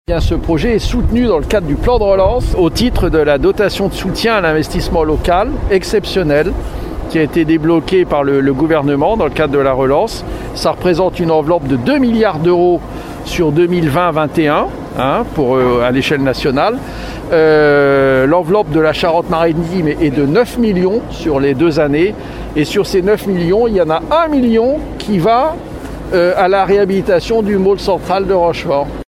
Et l’Etat participe à cette réhabilitation à hauteur d’1,1 million d’euros dans le cadre du Plan France relance, comme nous l’explique le préfet Nicolas Basselier qui s’est rendu sur place ce matin, à l’occasion d’une visite de chantier :